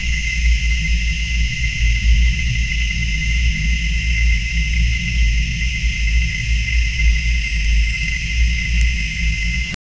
hot spring ambience 0:15 sound of an onsen 0:30 Dragon hiss with bubbling water, wave crash impact, serpentine slash 0:01 Looping ambient sound of an old swamp water pump: deep metallic hum, rusty groans, pipe vibration, bubbling water, distant insects. Eerie and decayed atmosphere, inspired by S.T.A.L.K.E.R. Ideal for abandoned Zone environment. 0:10
looping-ambient-sound-of--tkrkrpn3.wav